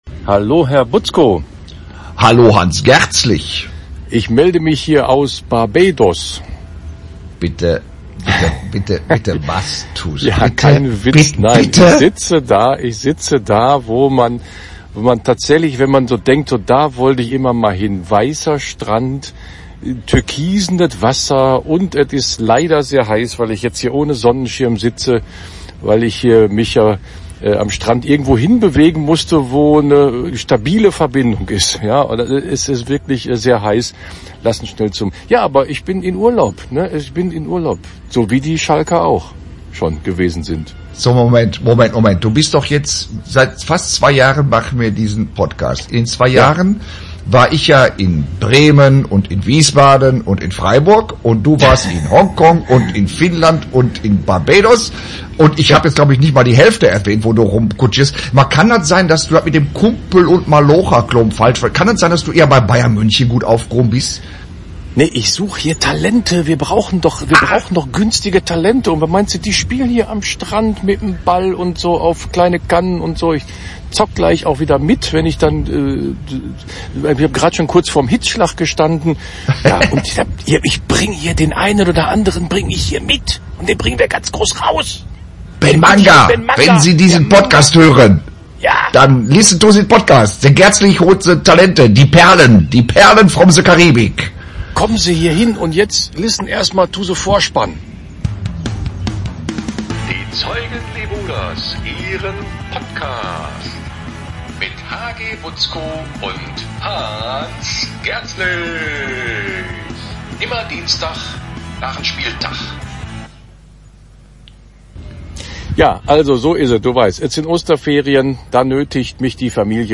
Außerdem quatschen sie seit über 20 Jahren über den S04 - und ab jetzt lassen sie das Aufnahmegerät mitlaufen.